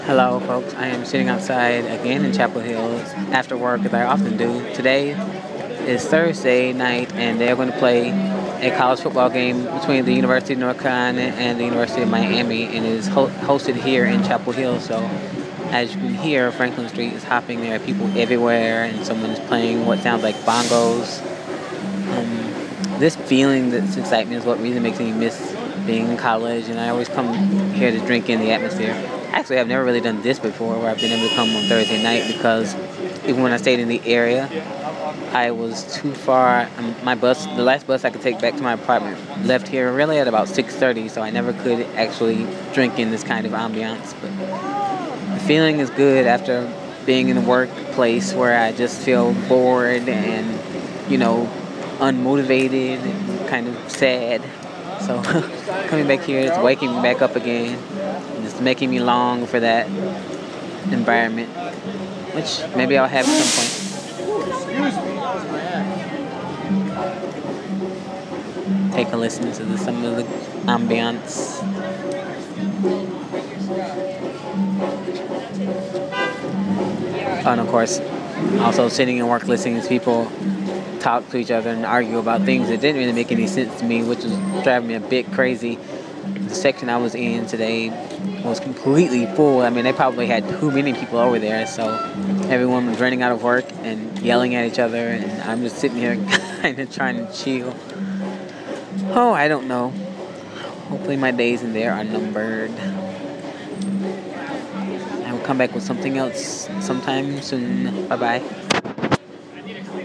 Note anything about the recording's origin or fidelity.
Sounds before A College Football Game